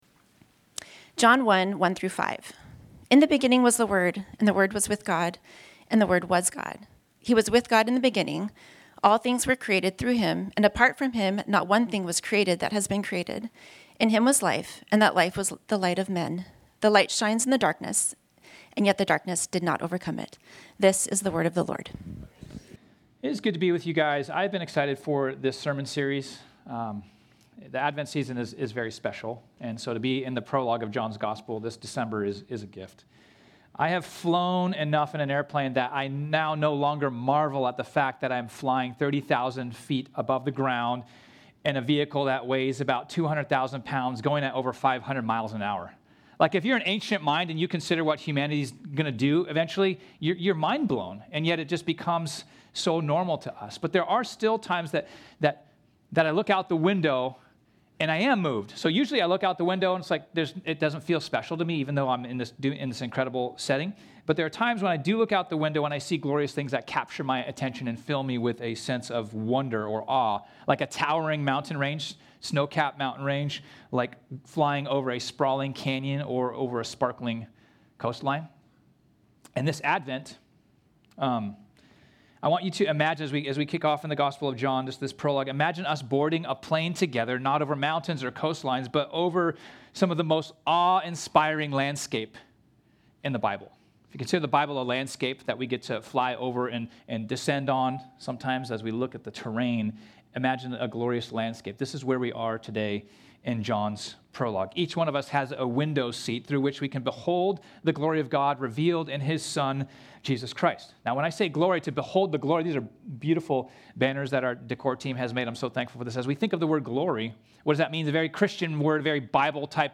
This sermon was originally preached on Sunday, December 7, 2025.